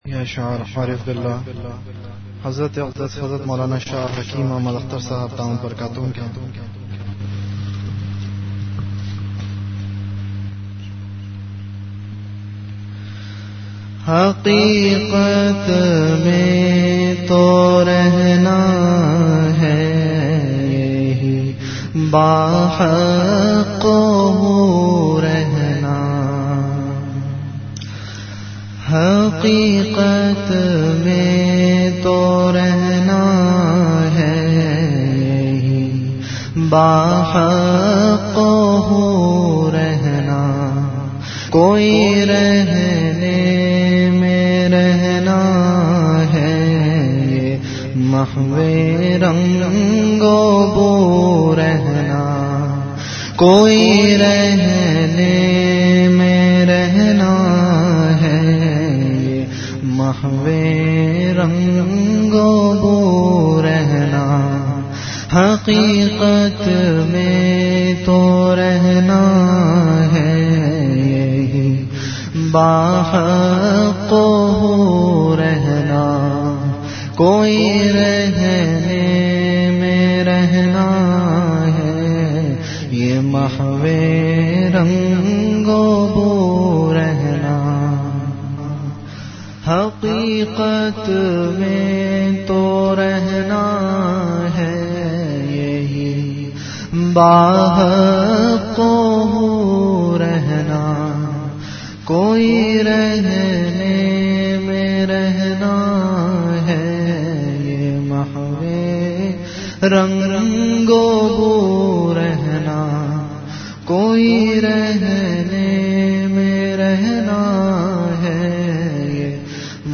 Ashaar
Download MP3 Share on WhatsApp Audio Details Category Ashaar Duration 16 min Date Islamic Date 1429 - 16 Rajab Venue Home Event / Time After Isha Prayer Listeners 1,032 File Size 3 MB Have a question or thought about this bayan?